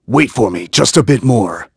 Clause_ice-Vox_Victory.wav